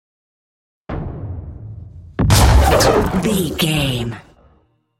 Double hit with whoosh shot explosion
Sound Effects
intense
woosh to hit